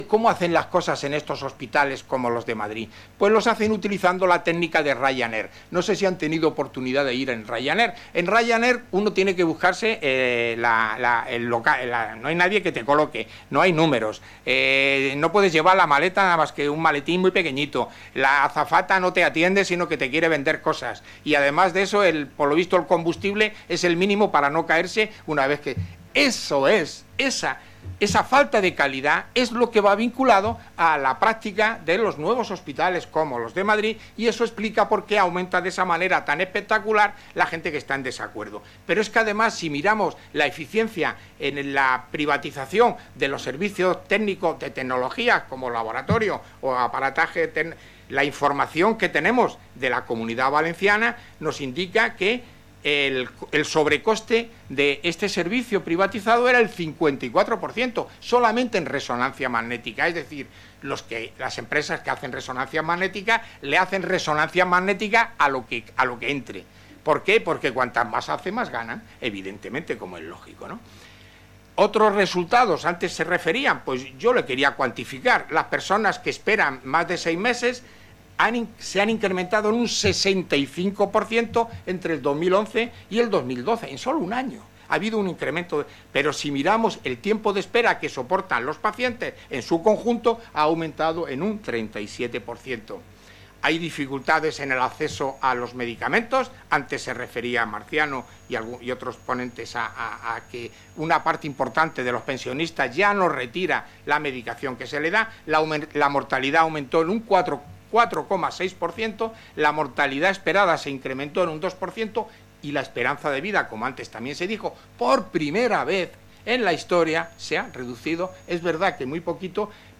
Jornada sobre un año de recortes en la sanidad pública.